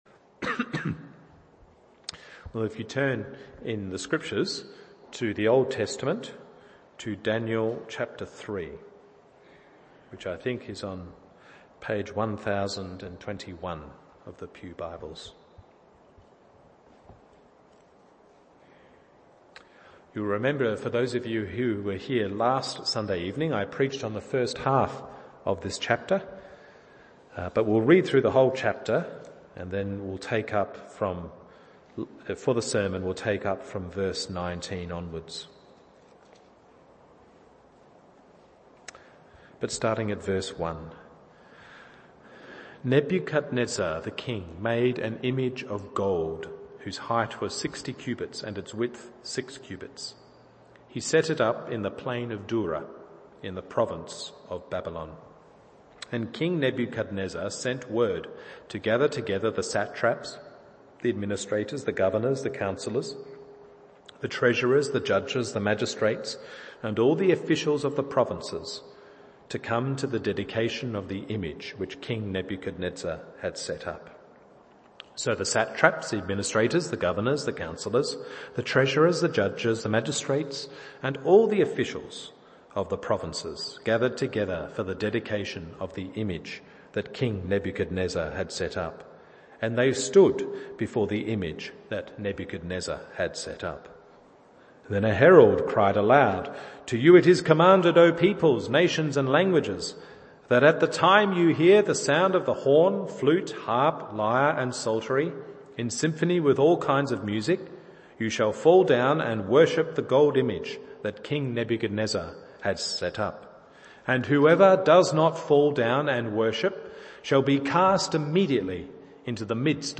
Evening Service Daniel 3…